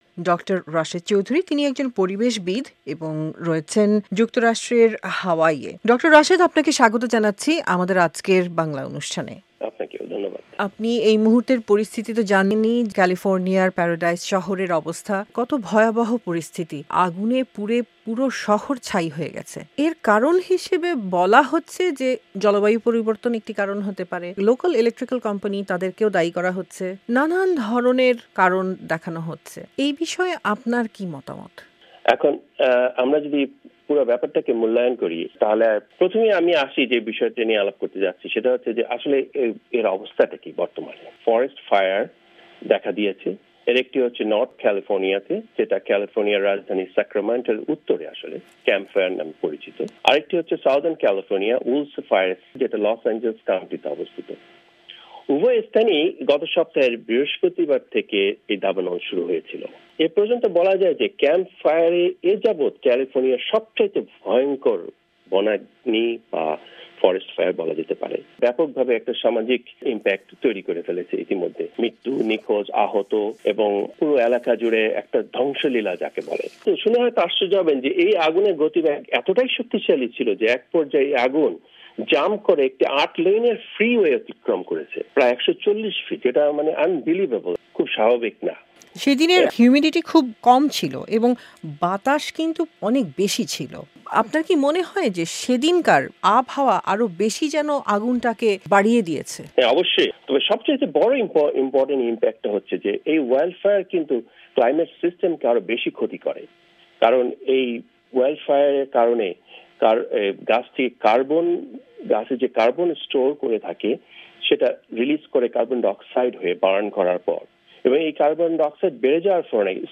সাক্ষাৎকার